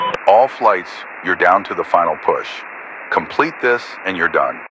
Radio-commandObjectivesOneLeft3.ogg